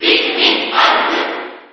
File:Alph Cheer JP SSB4.ogg